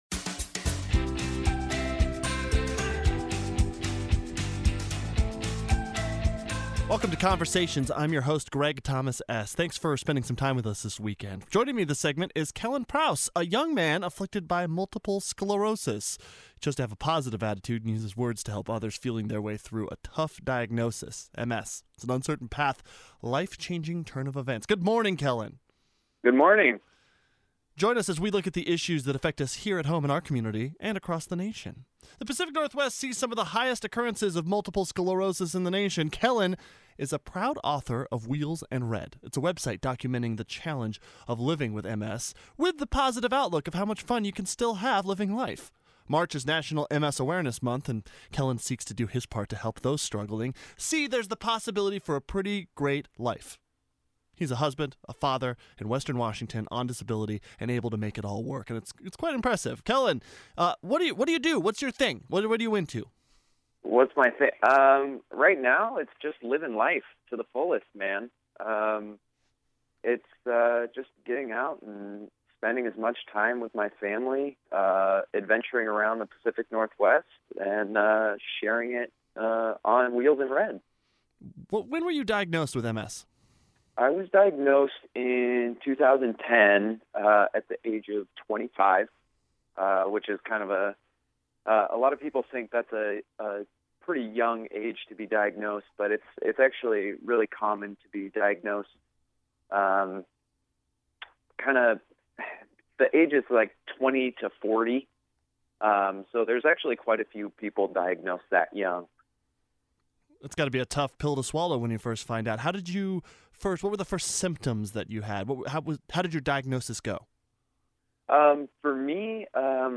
This is the public affairs program “Conversations”, that runs 6 a.m. Sunday mornings on 107.7 The End.